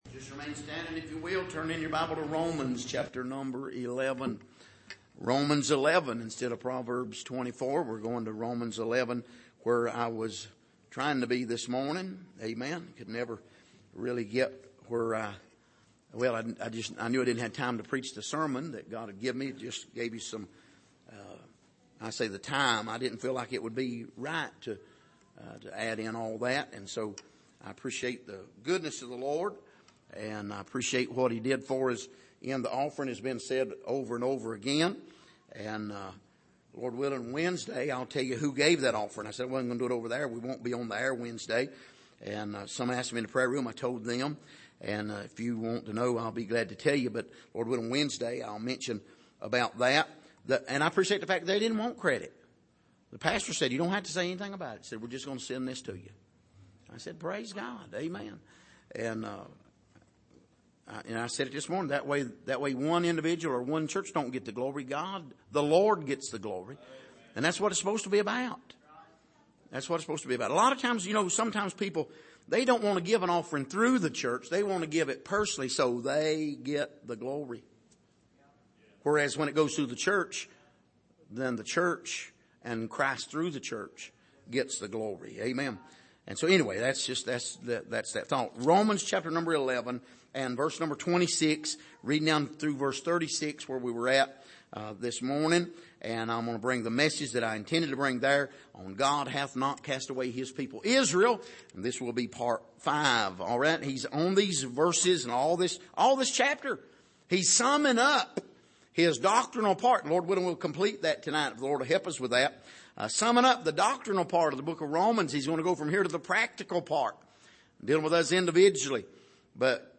Passage: Romans 11:26-36 Service: Sunday Evening